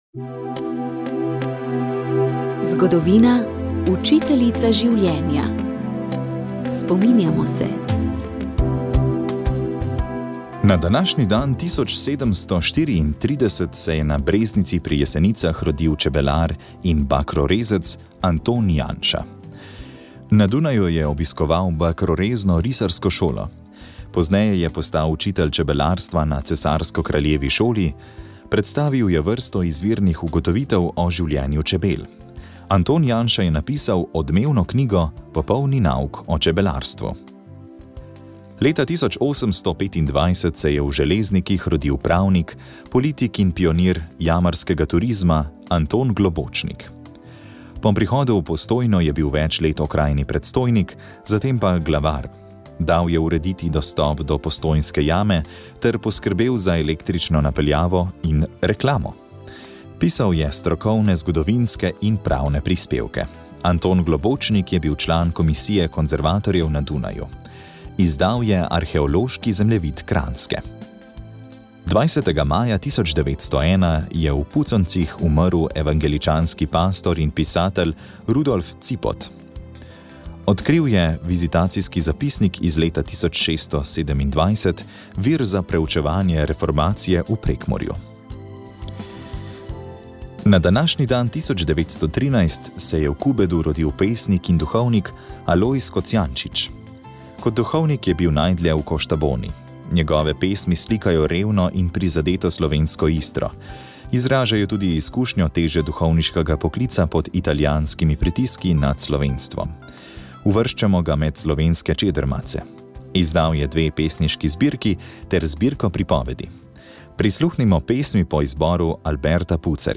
Tokrat smo gostili glasbeni dvojec, ki navdušuje z nežnostjo, virtuoznostjo in odrsko ubranostjo – duo Claripiano.